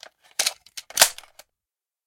magReload.ogg